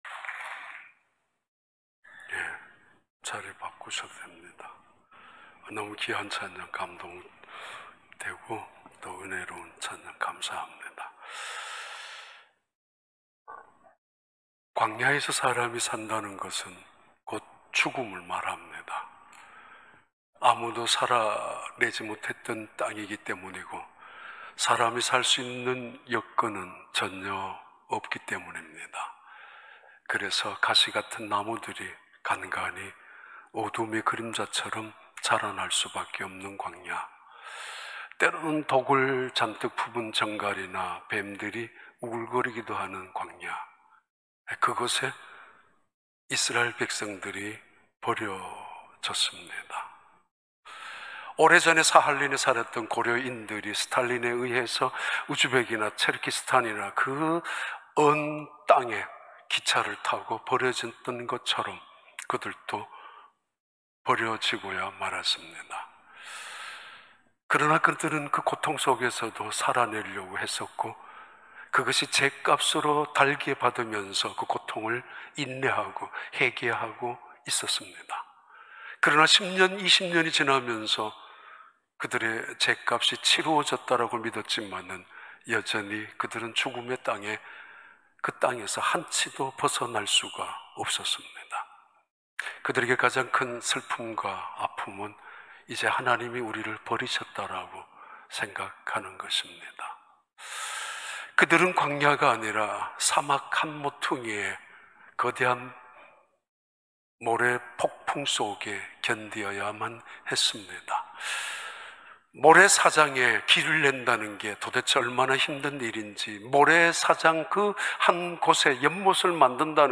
2021년 6월 13일 주일 4부 예배